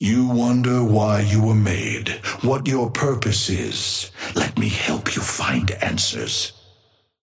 Amber Hand voice line - You wonder why you were made, what your purpose is.
Patron_male_ally_viscous_start_01.mp3